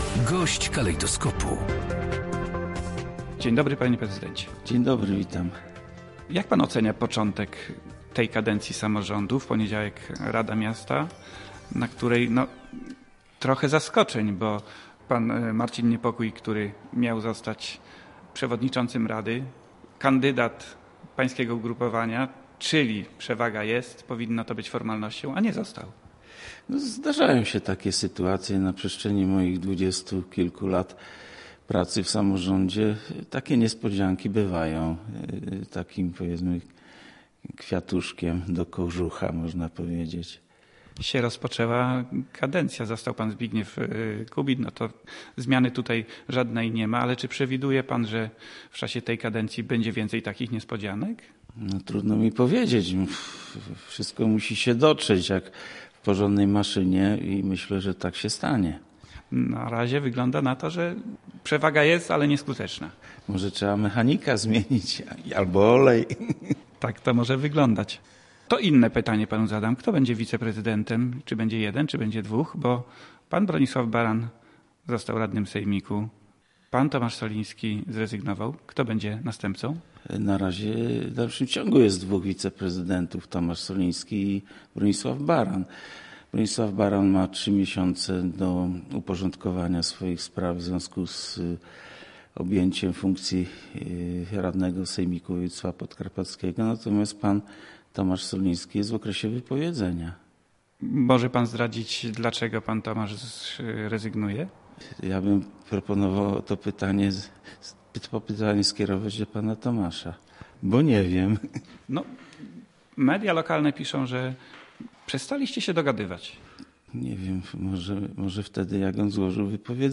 Przytocki, który był gościem Polskiego Radia Rzeszów przyznaje, że został tą decyzją zaskoczony.